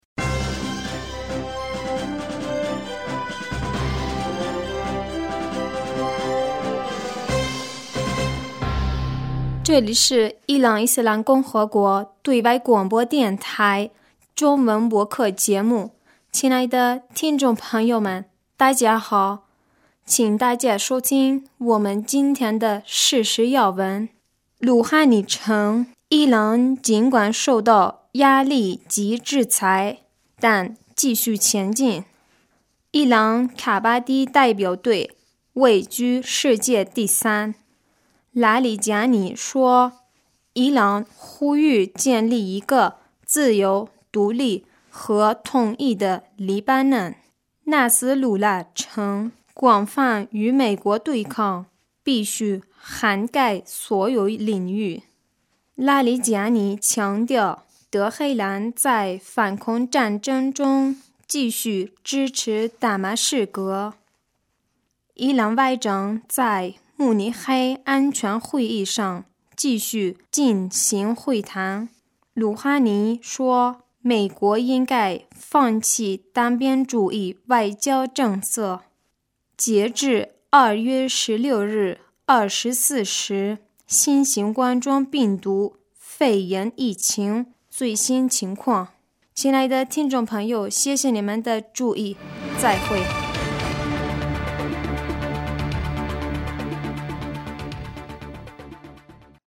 2020年02月17日 新闻